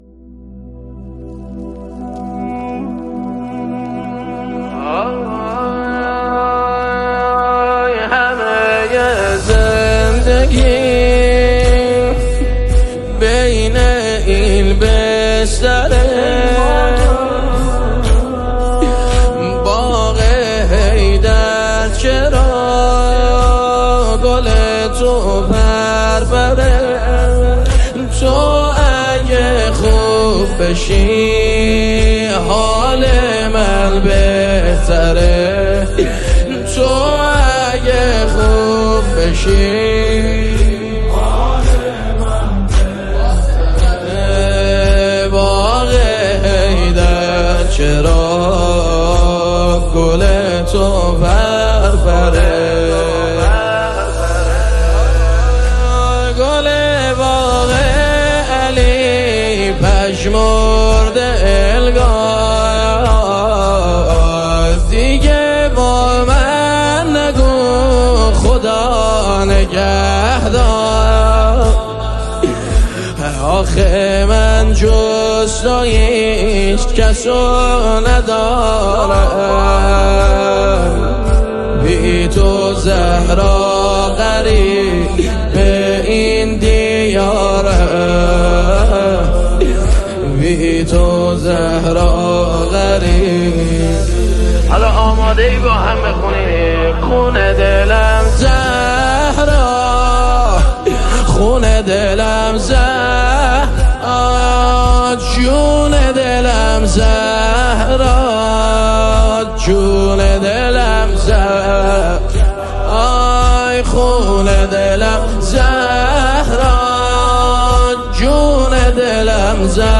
ویژه ایام فاطمیه